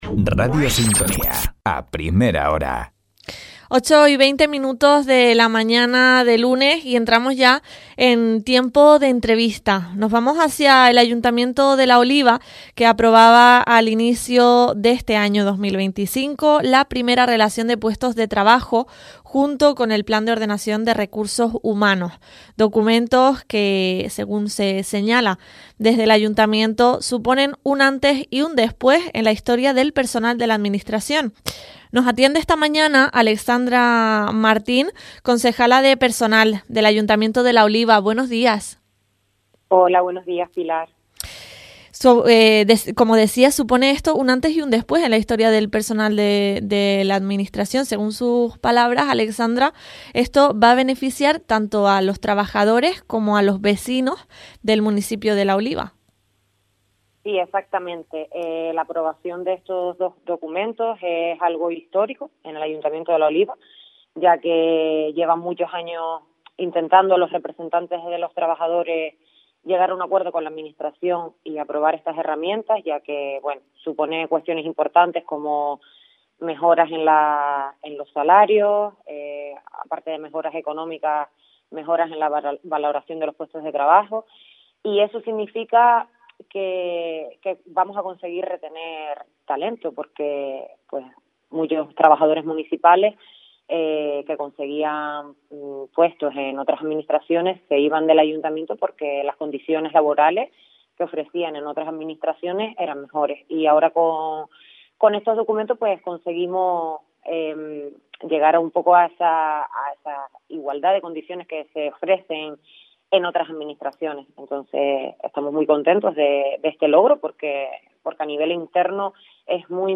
Hemos conocido estos instrumentos de la mano de la concejala de Personal del Ayuntamiento de La Oliva, Alexandra Martín.
Entrevistas